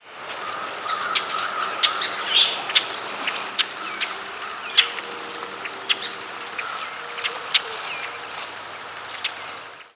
Rusty Blackbird diurnal flight call
Diurnal calling sequences: